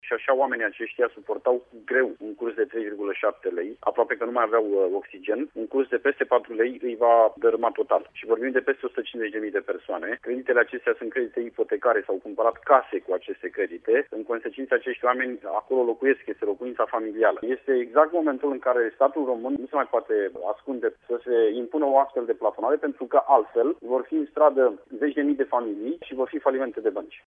Avocatul Gheorghe Piperea
Avocatul Gheorghe Piperea, unul dintre cei care i-au reprezentat în justiţie pe mai mulţi clienţi ai băncilor care au de plătit rate în franci elveţieni, atrage atenţia asupra dificultăţilor majore pe care aceştia le vor întâmpina: